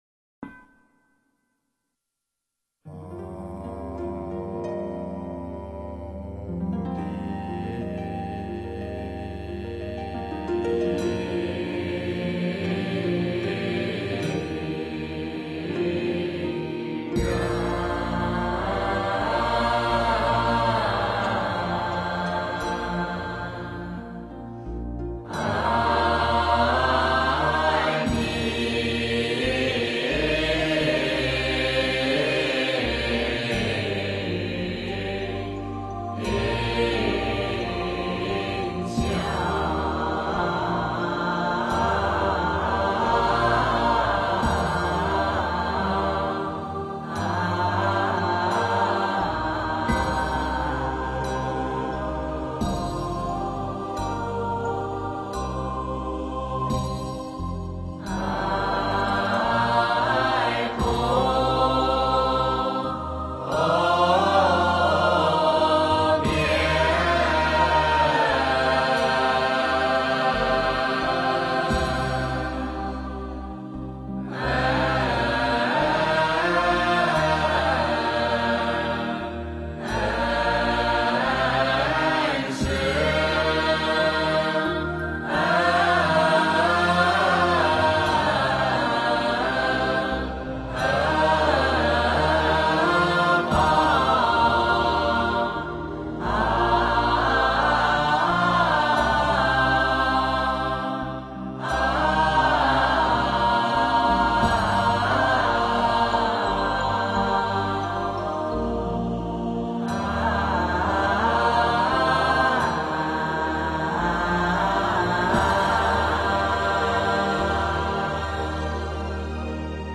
宝鼎赞 诵经 宝鼎赞--如是我闻 点我： 标签: 佛音 诵经 佛教音乐 返回列表 上一篇： 八十八佛大忏悔文 下一篇： 大悲十小咒 般若波罗蜜多心经 相关文章 一袖云--刘珂矣 一袖云--刘珂矣...